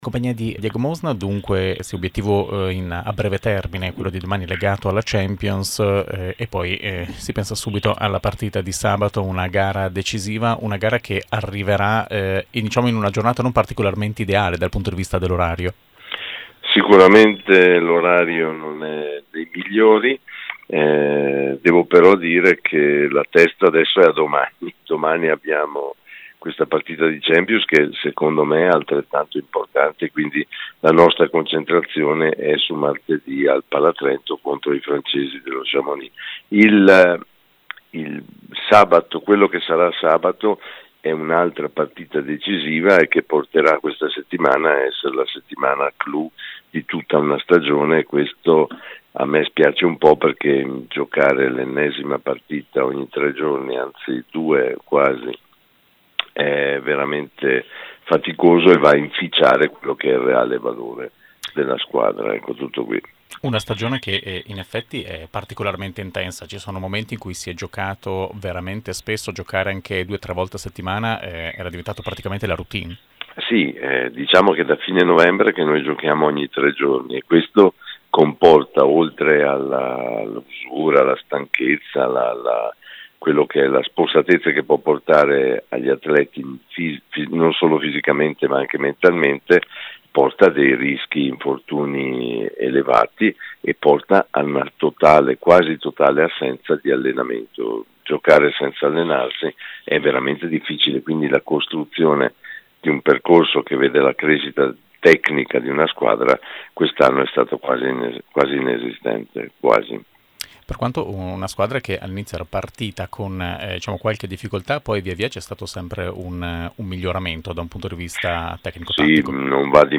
Interviste mp3
intervistato da Radio Dolomiti